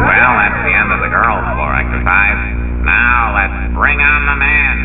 When Smithers turns on his computer, you hear Mr BURNS voice say this at startup.......